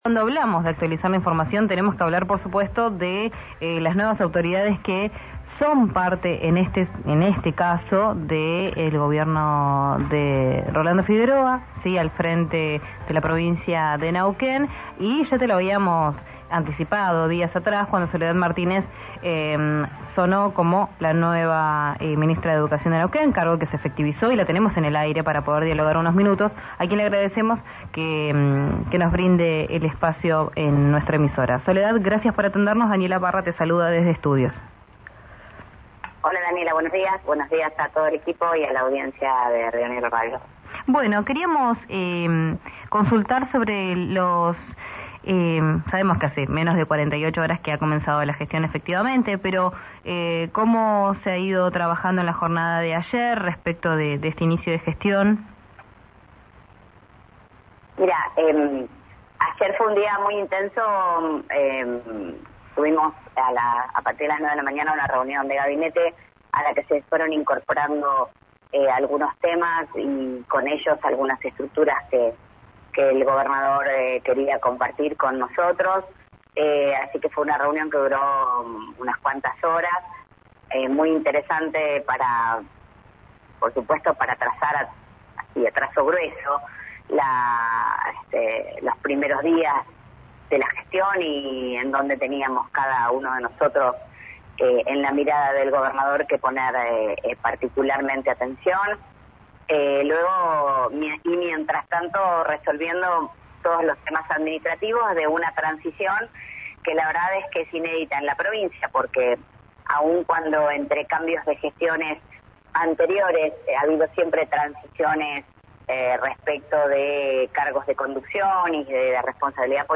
En diálogo con RÍO NEGRO RADIO, la flamante funcionaria que asumió con Rolando Figueroa el domingo destacó el plan de becas que se aprobó en la Legislatura y auguó un buen diálogo con ATEN y ATE en su gestión.